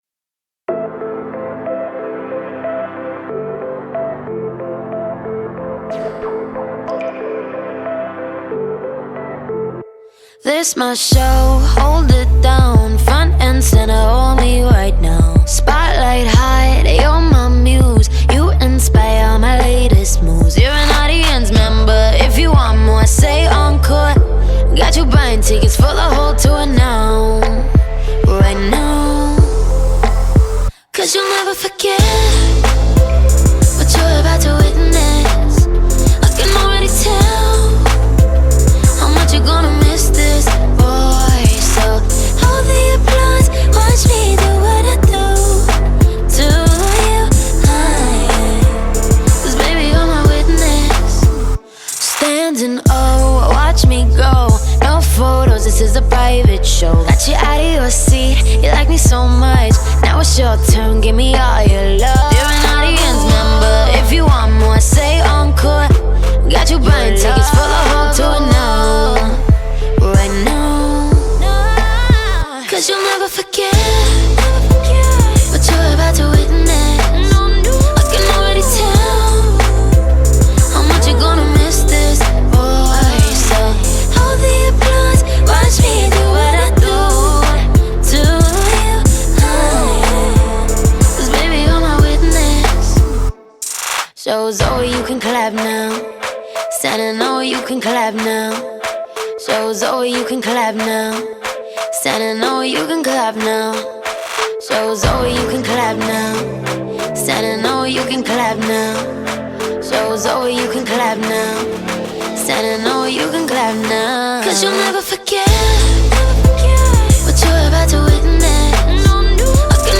это энергичная поп-песня